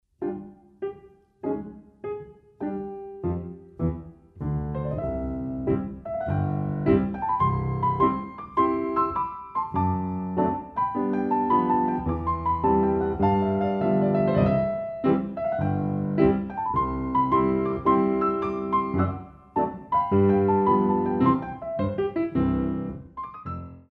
Young dancers Ballet Class
The CD is beautifully recorded on a Steinway piano.
Dégagés décomposé